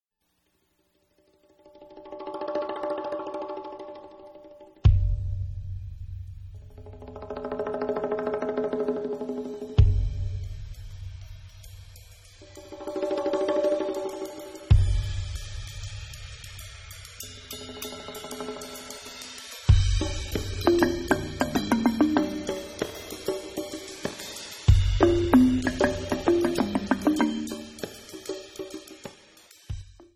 The results are equal parts breathtaking and booty shaking.
And it all sounds so easy.
Funk
Fusion
Progressive Rock
Psychedelic
Rock